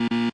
error.opus